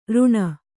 ♪ řṇa